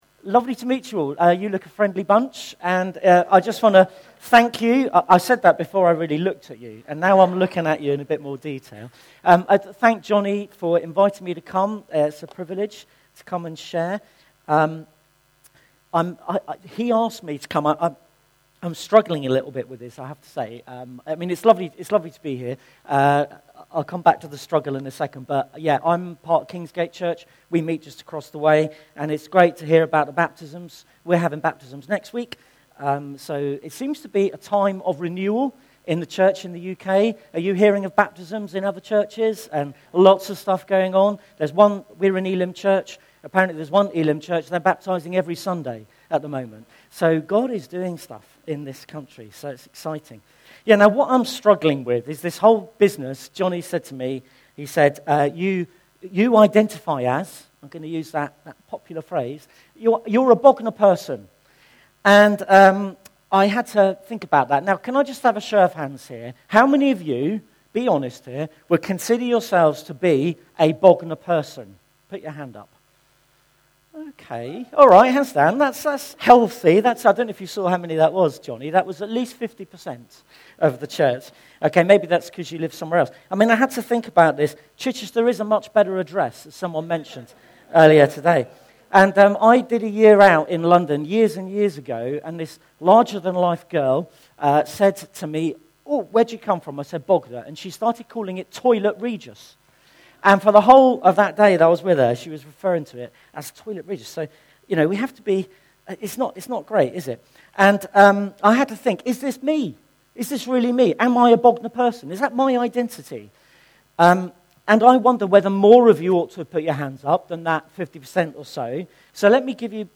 Grace Church Sunday Teaching Are You On the Way?